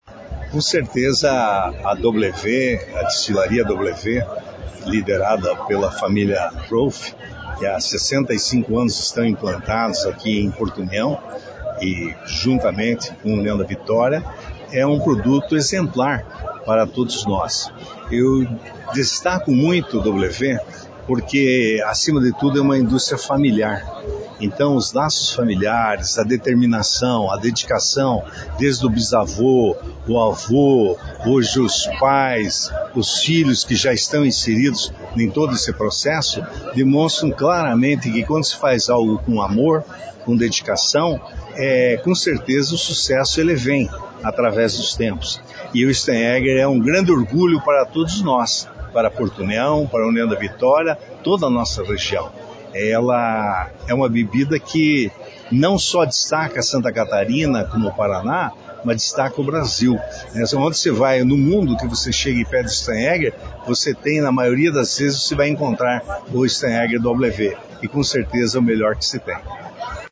O prefeito de União da Vitória esteve presente e também falou da contribuição que o fabricante do Steihaeger traz para o fortalecimento da região e da marca que leva o nome de Porto União para todo o Brasil.